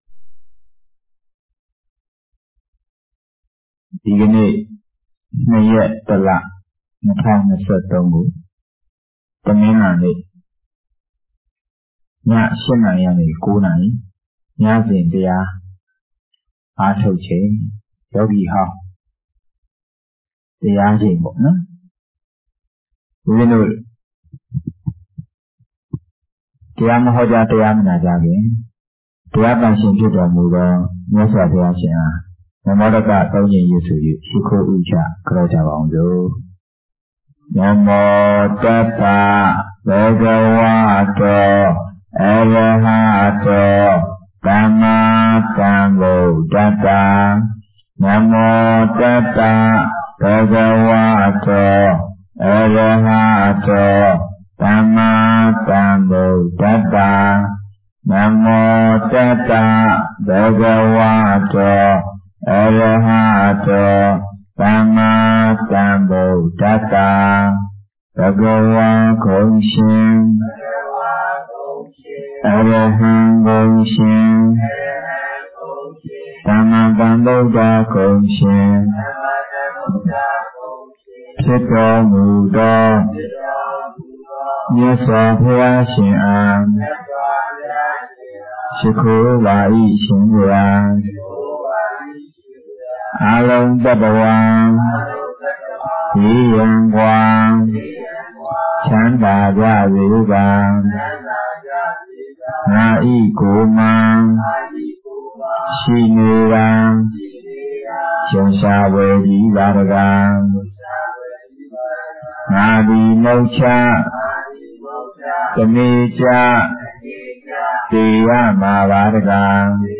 Jan02 2023 ညစဉ်တရားပွဲ